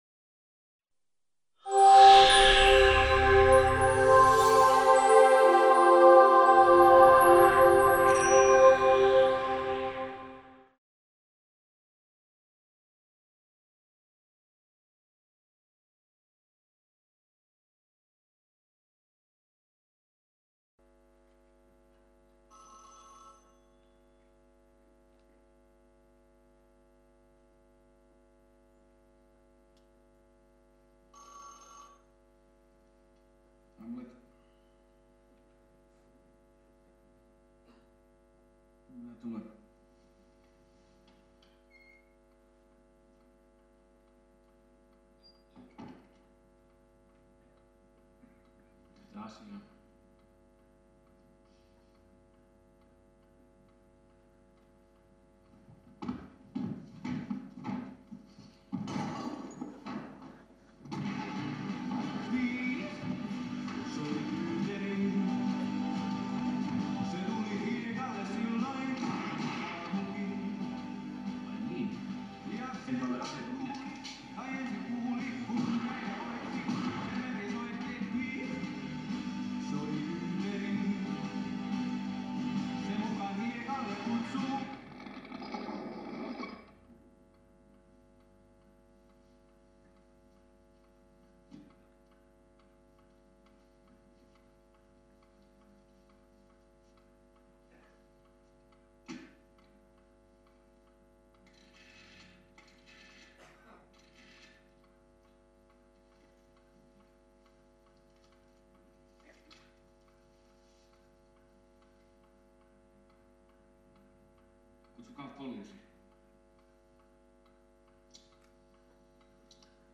A l'occasion de la rétrospective intégrale que le Festival Paris Cinéma lui a consacré du 1er au 12 juillet, une rencontre avec le cinéaste finandais pour mieux comprendre son oeuvre et ses influences.